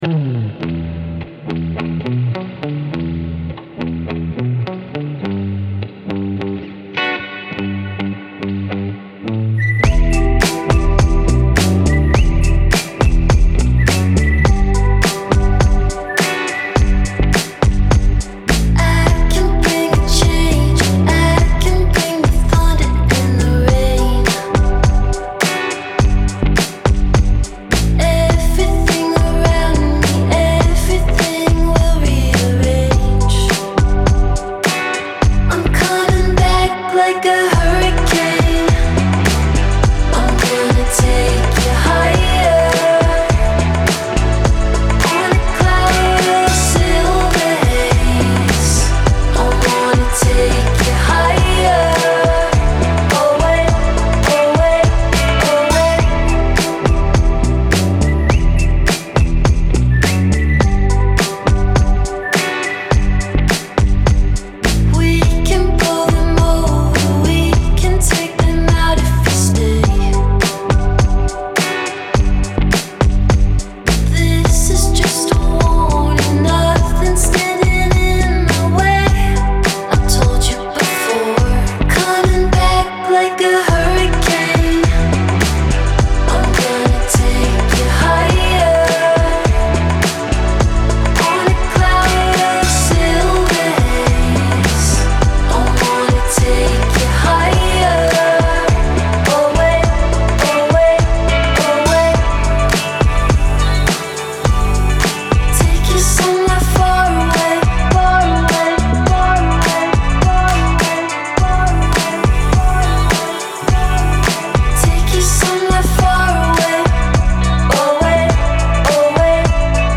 BPM104-104
Audio QualityPerfect (High Quality)
Indie Pop song for StepMania, ITGmania, Project Outfox
Full Length Song (not arcade length cut)